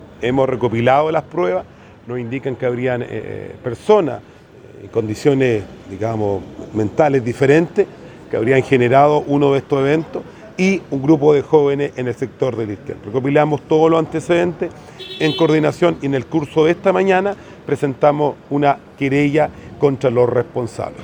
Fue en medio de un Cogrid donde el alcalde de Penco, Rodrigo Vera, reportó una serie de focos de incendios forestales que se han iniciado en los últimos días y que tienen características intencionales.